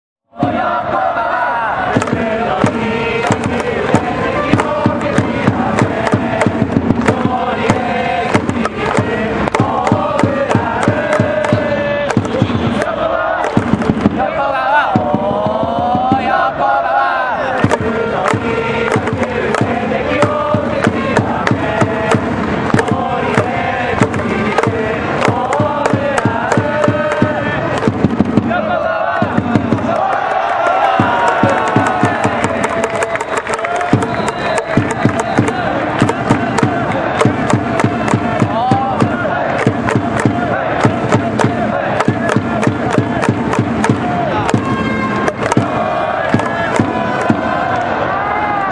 選手応援歌